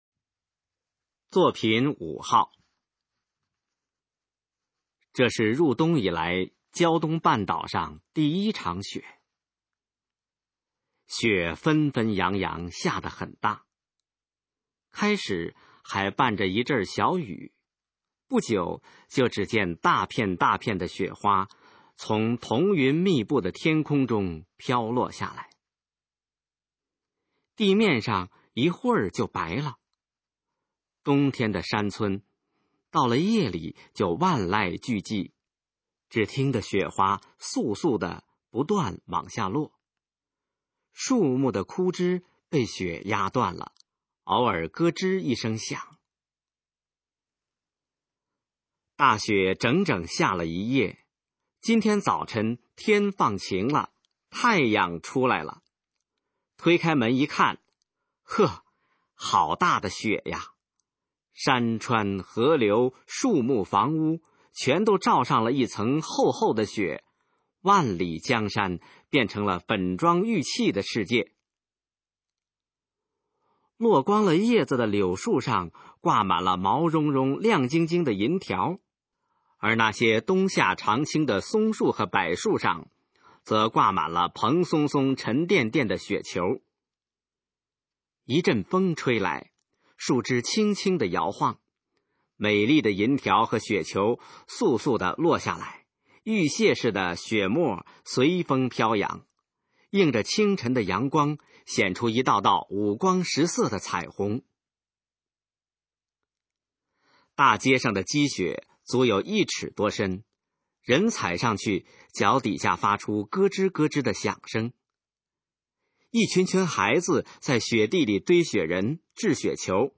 《第一场雪》示范朗读_水平测试（等级考试）用60篇朗读作品范读